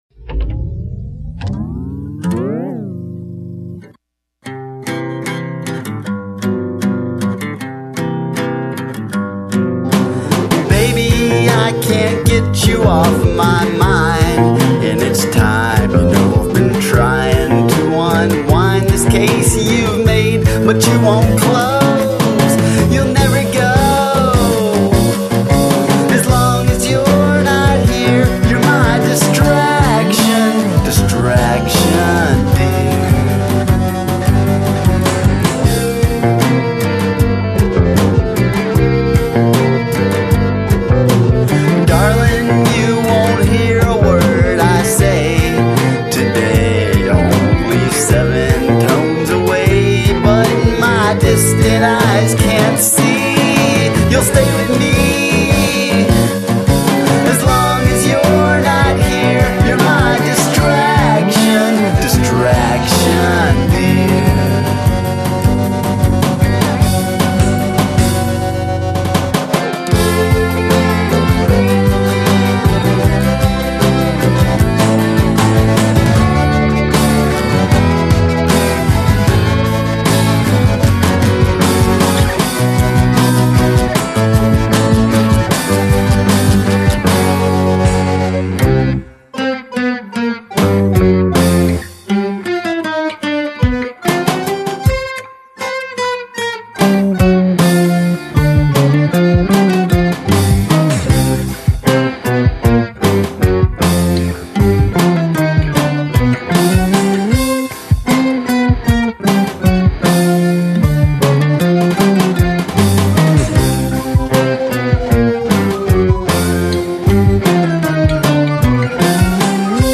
Quirkadelic Rock